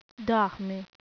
In this page, you can hear some brazilian portuguese words/phrases.